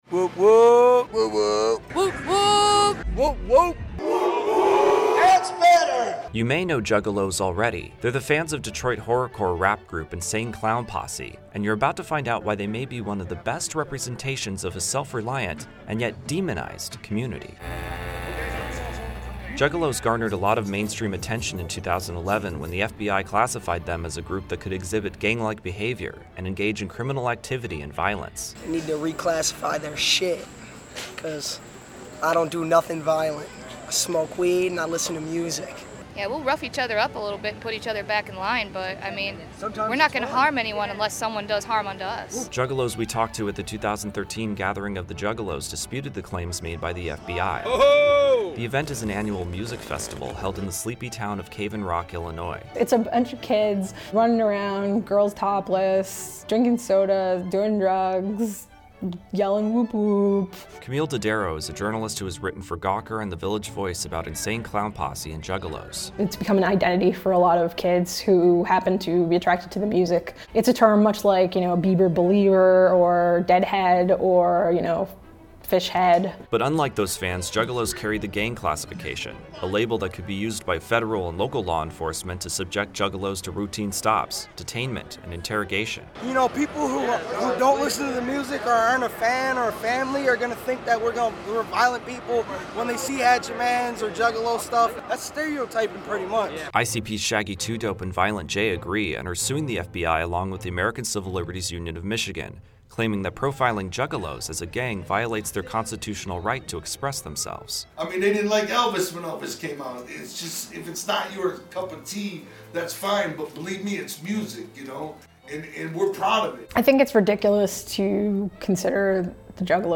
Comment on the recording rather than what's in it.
Juggalos at the 2013 Gathering of the Juggalos, a music festival held in Cave-in-Rock, Illinois, told Reason TV that they disputed the claims made by the FBI.